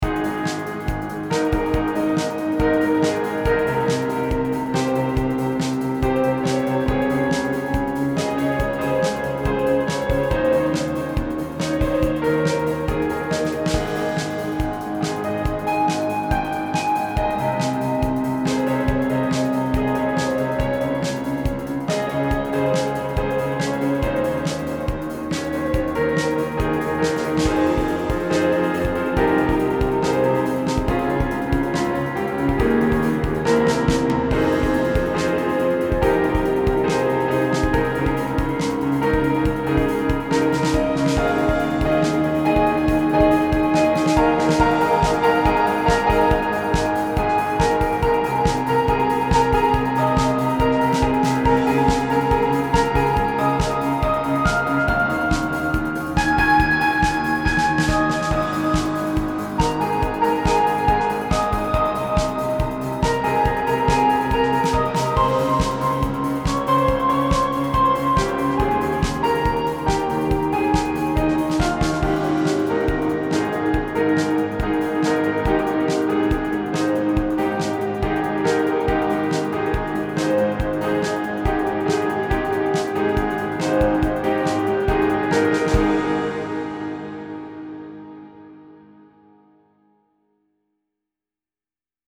未分類 ピアノ 疾走感 青春 音楽日記 よかったらシェアしてね！